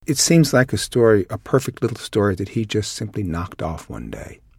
As can be observed, in all the cases above the particle of the phrasal verb, despite being a function element, bears the nucleus of the IP.
[1] The final time adverbial is unaccented.